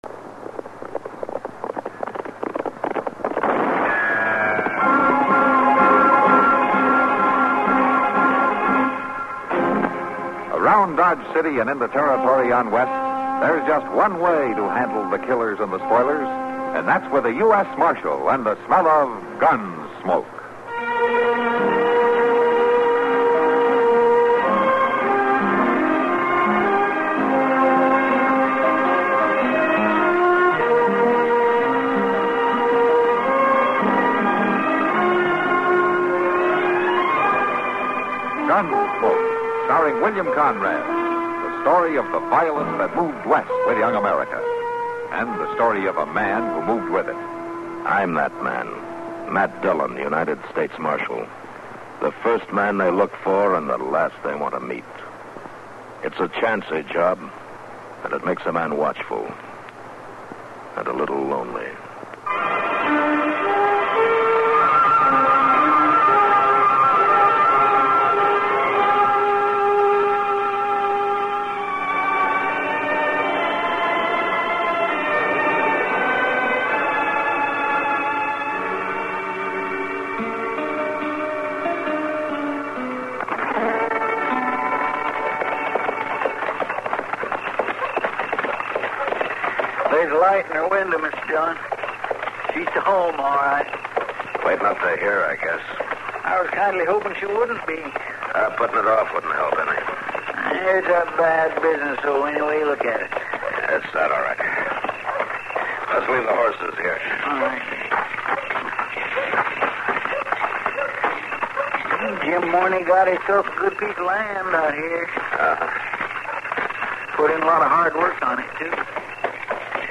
Gunsmoke is an American radio and television Western drama series created by director Norman Macdonnell and writer John Meston. The stories take place in and around Dodge City, Kansas, during the settlement of the American West. The central character is lawman Marshal Matt Dillon, played by William Conrad on radio and James Arness on television.